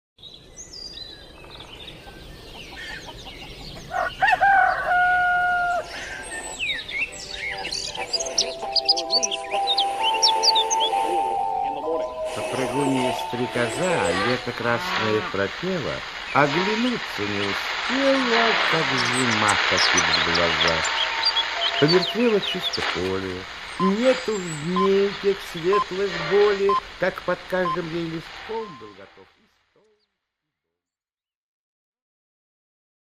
Аудиокнига Стрекоза и Муравей. Часть 2 | Библиотека аудиокниг